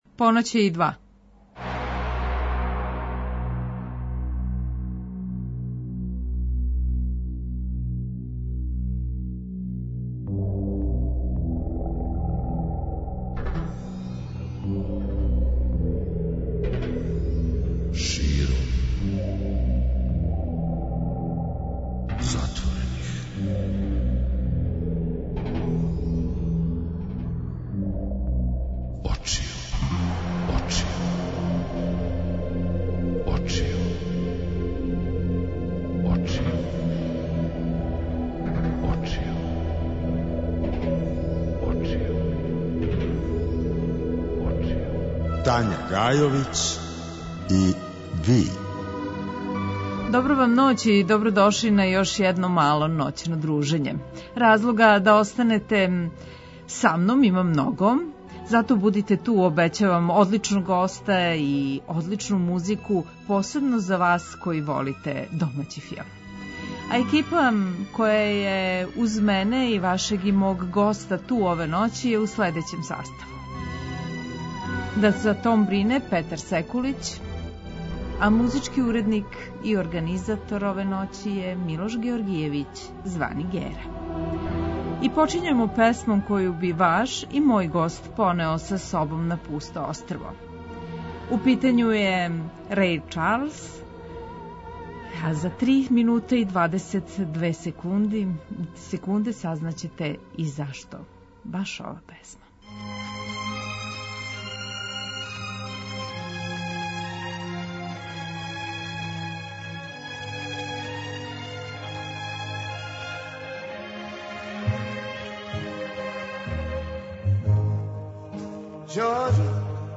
Гост: Зоран Симјановић, композитор